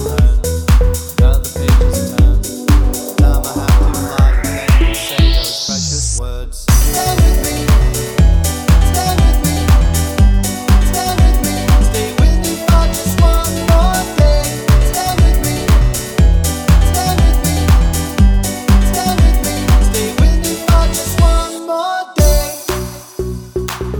no Backing Vocals Dance 3:26 Buy £1.50